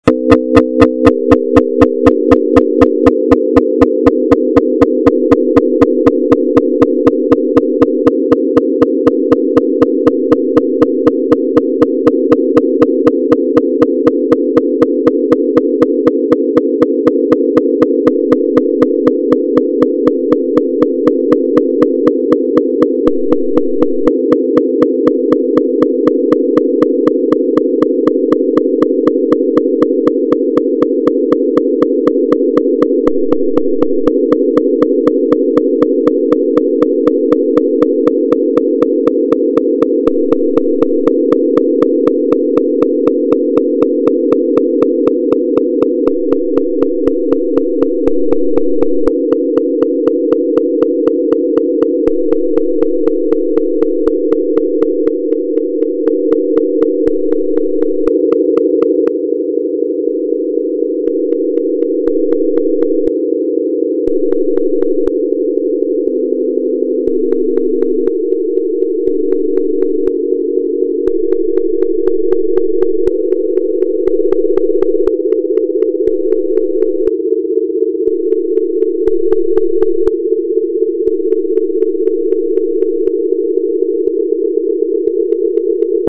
Sonification 4b: Closer tones
This should lead us to have lots of beating early on and then start to pick out individual sounds. This sounds like a demented Edgard Vare’se to my ear…there are some interesting modulations, but it is still too sonically crowded.